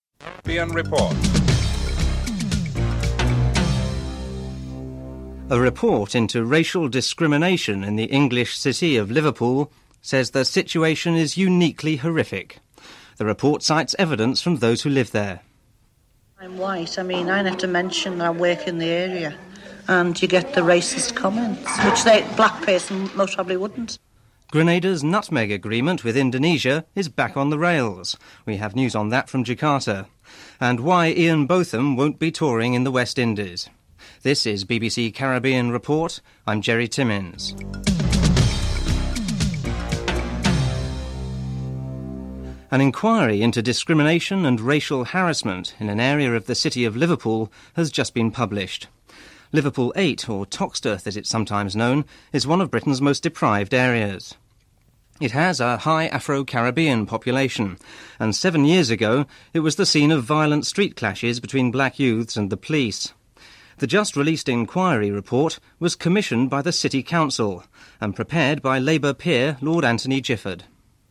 1. Headlines (00:00-00:33)
3. Financial News (07:27-09:00)